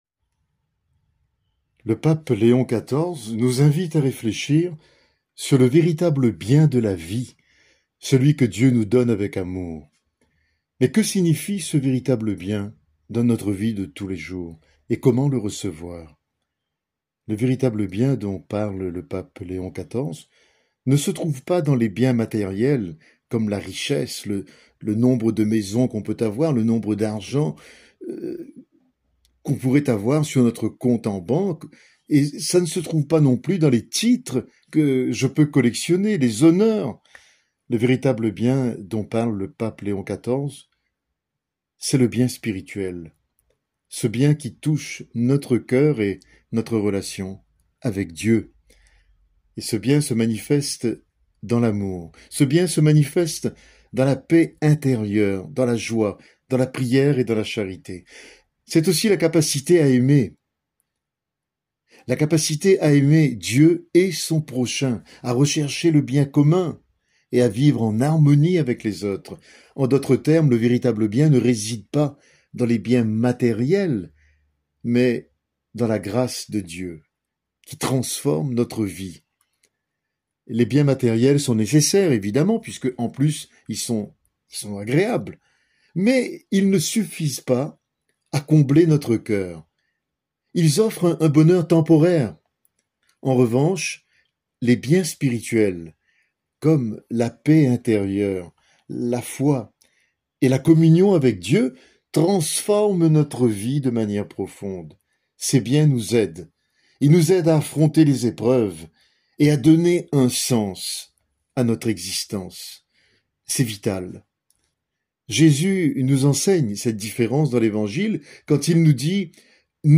Prédication disponible en format audio.